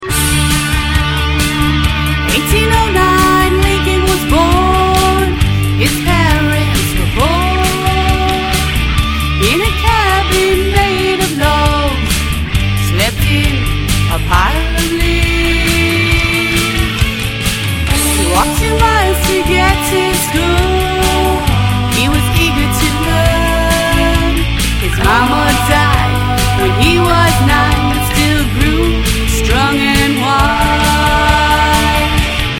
MP3 Demo Vocal Track